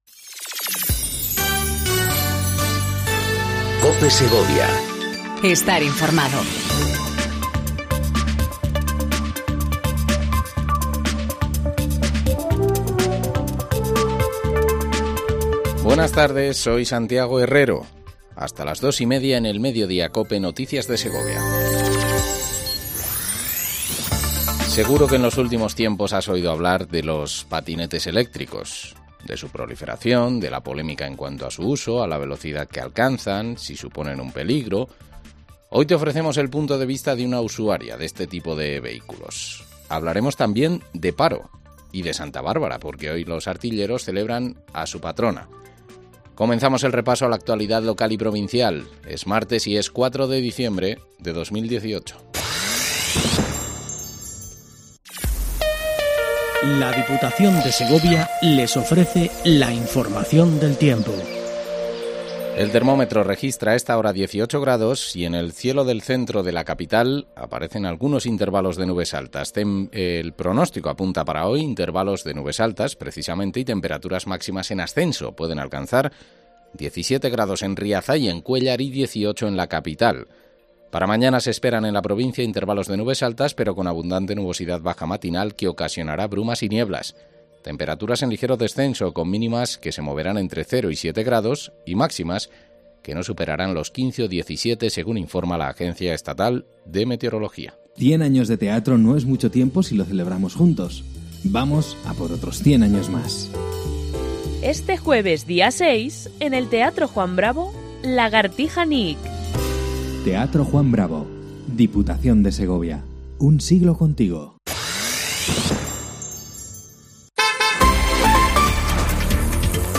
INFORMATIVO MEDIODÍA COPE SEGOVIA 14:20 DEL 04/12/18